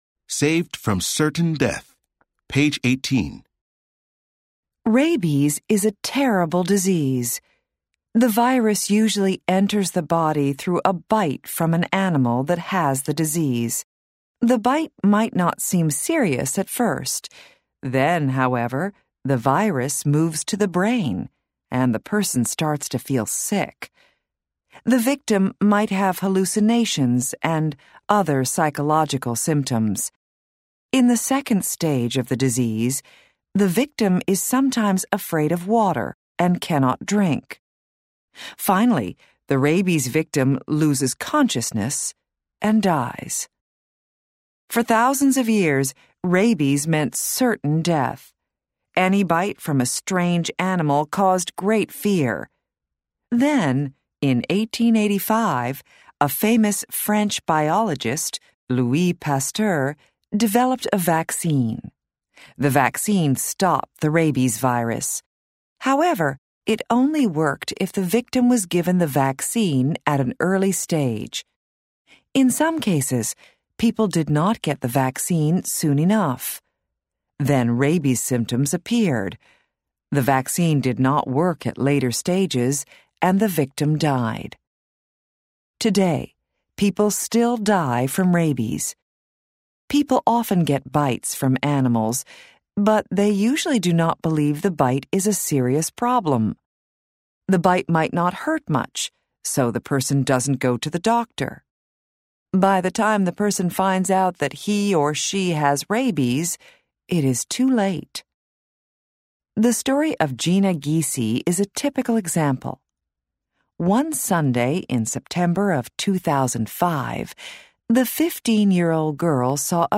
Bound into the back of the book is an audio CD that contains audio recordings of all the stories in the Student's Book.